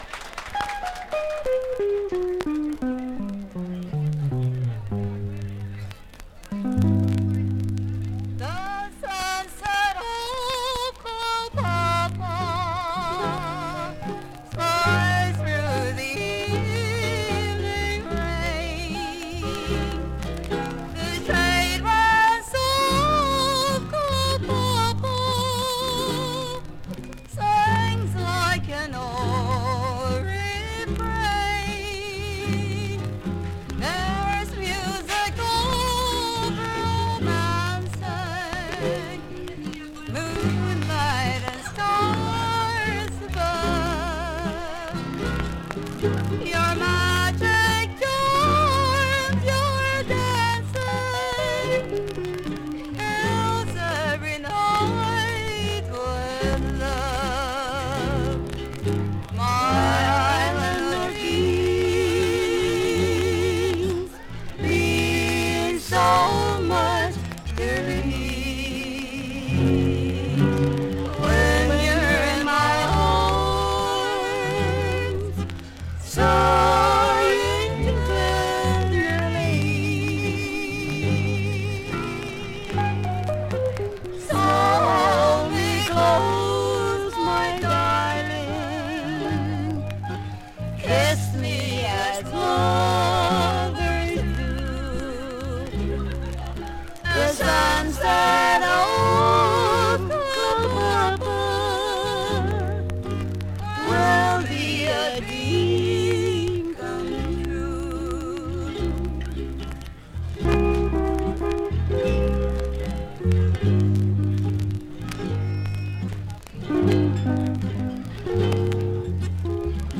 Voice | Downloadable
Historical Recording